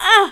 Hurt grunt 1.mp3
Hurt_grunt_1.mp3